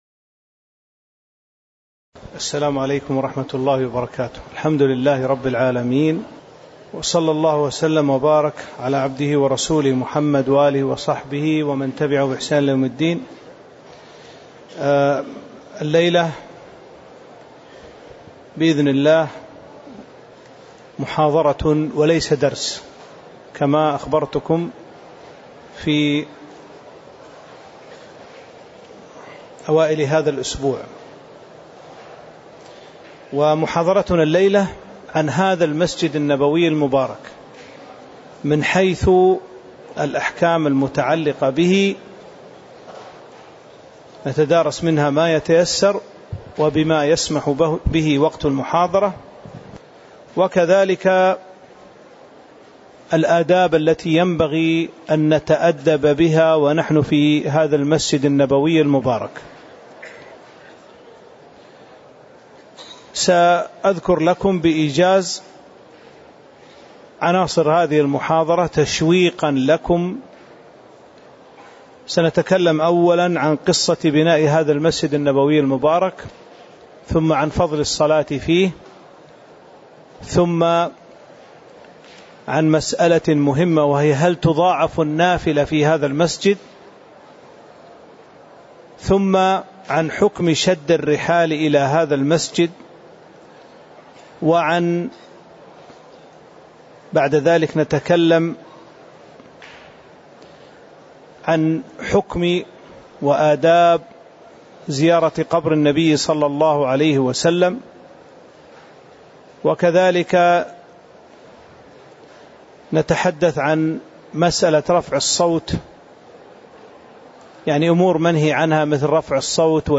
تاريخ النشر ٢٣ ذو الحجة ١٤٤٤ هـ المكان: المسجد النبوي الشيخ